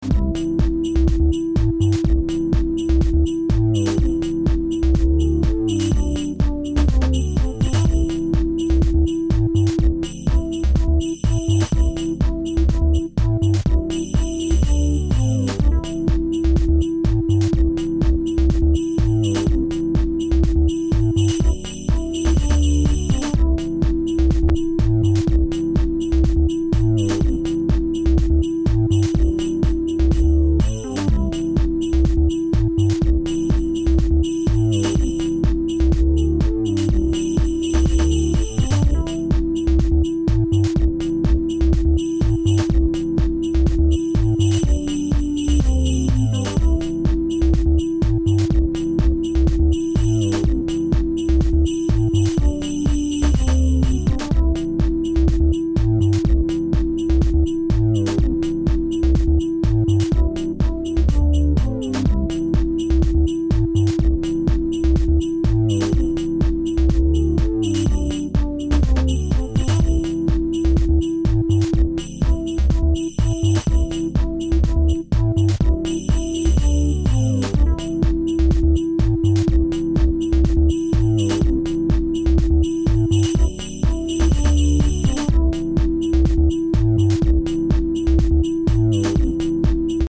Prescription初期を思わせるファンクネスとディープさを兼ね備えたアンダーグラウンド・シカゴを体現した傑作盤！